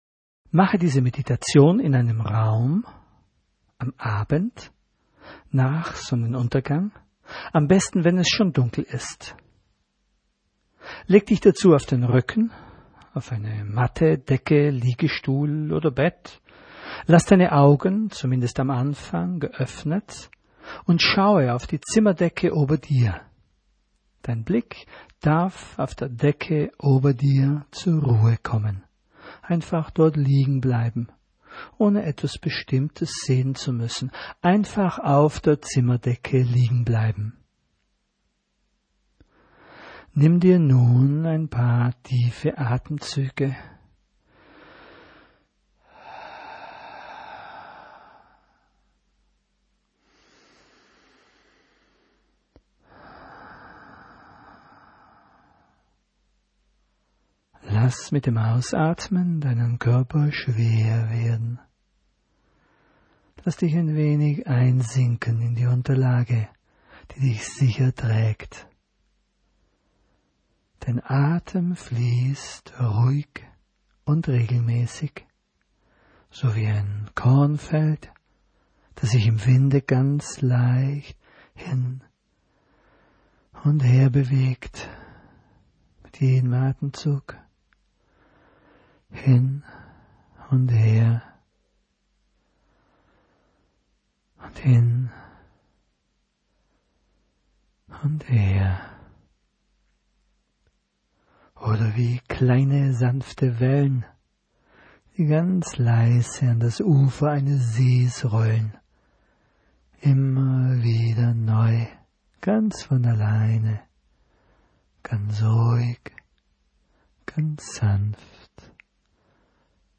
Achtung: in der Mitte gibt es einen längeren Block mit Stille, das ist kein Fehler, sondern ein wichtiger Bestandteil der Meditation.
Am Ende führt Sie die Stimme wieder zurück in die Gegenwart.
Relax04-Wolkenmeditation-Sterne.mp3